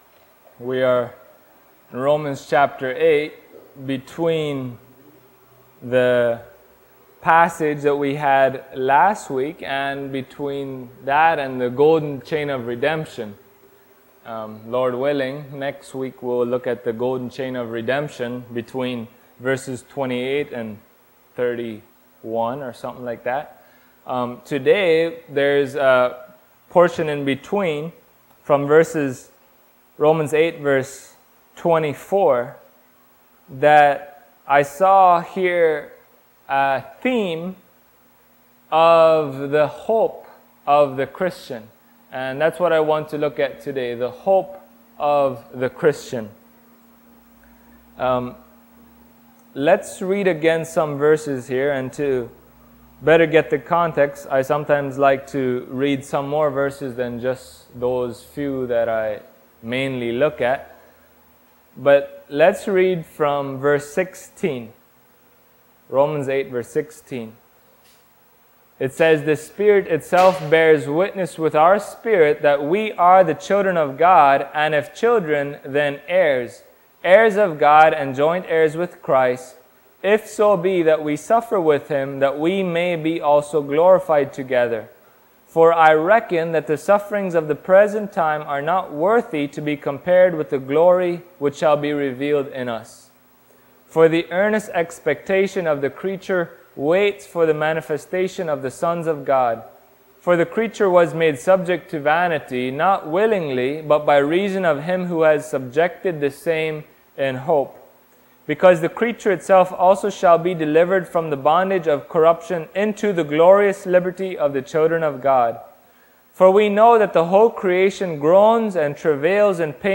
Romans 8:24-28 Service Type: Sunday Morning Topics